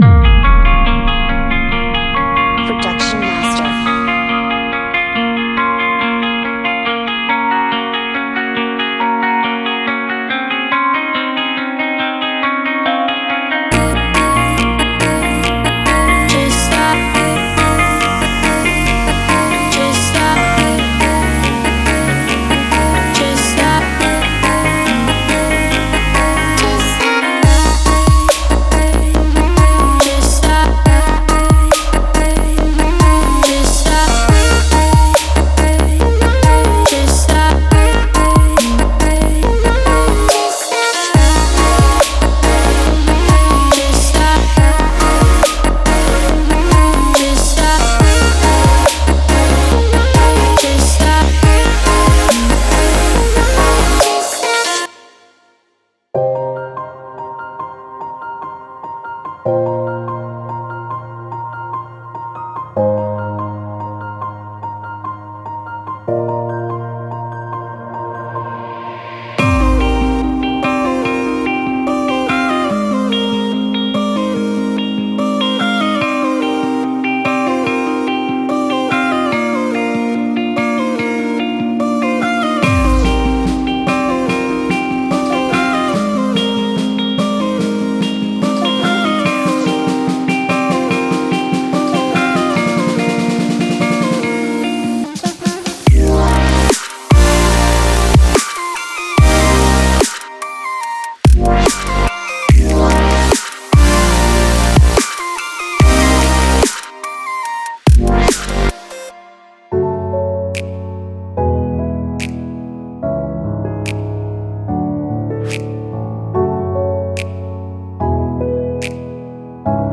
鼓舞人心的节奏感十足，鼓舞人心的节奏，精心设计的合成器和最先进的水晶制作：
• 9 Guitar Loops
• 23 Piano Loops
• 35Vocal Chop Loops